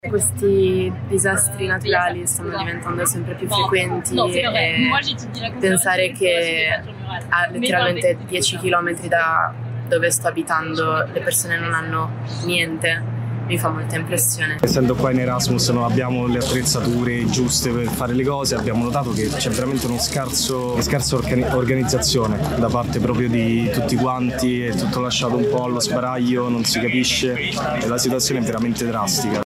Avete appena ascoltato alcune testimonianze degli studenti italiani in Erasmus a Valencia.